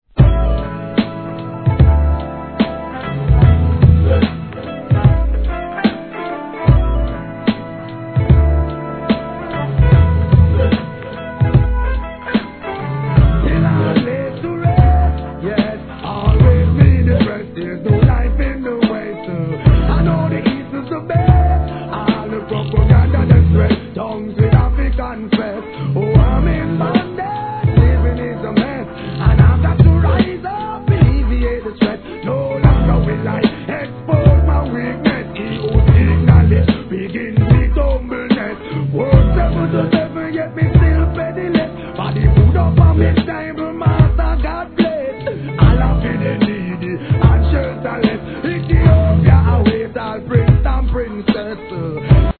HIP HOP/REGGARE/SOUL/FUNK/HOUSE/
REGGAE、HIP HOP、R&BブレンドREMIXシリーズ第4弾！